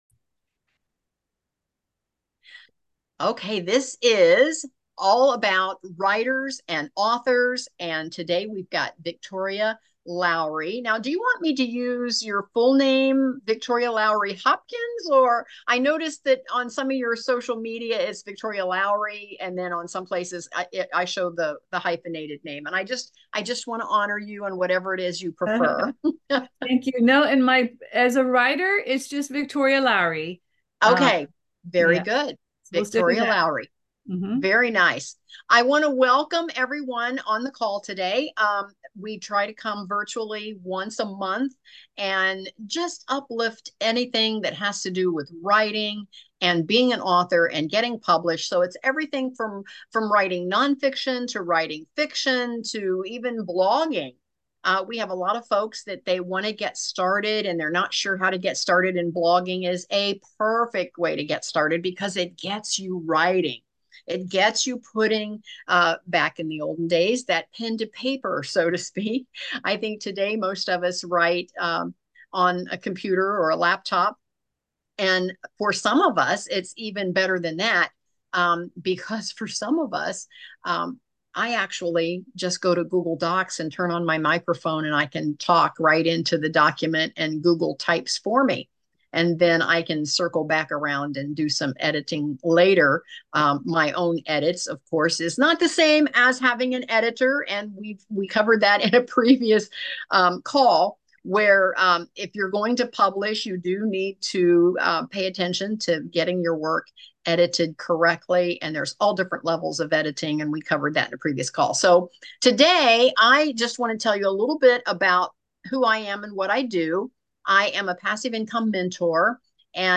Designing a Book Cover | Interview